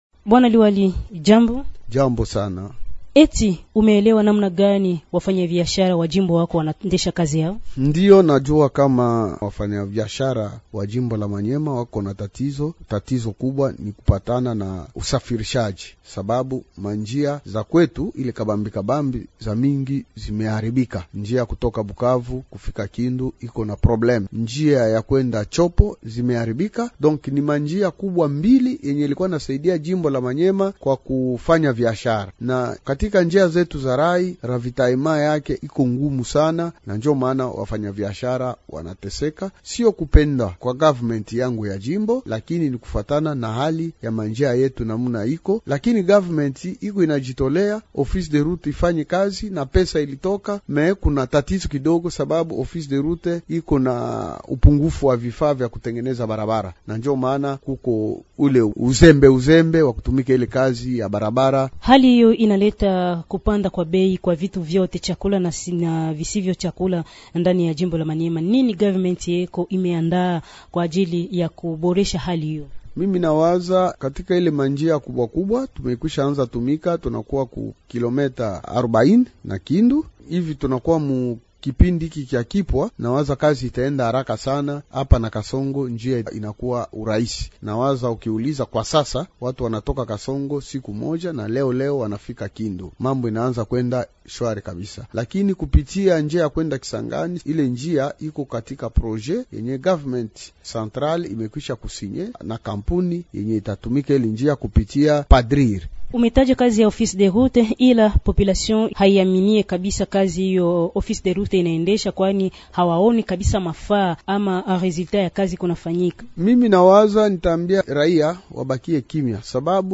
Mwalikwa wetu ni liwali wa muda wa jimbo la Maniema. Afani Idrissa  Mangala azungumza  kuhusu mazingira magumu ya kazi ya waendeshaji uchumi wa jimbo hilo  na hiyo  serikali jimboni  inafanya ili  kurekebisha hali hiyo.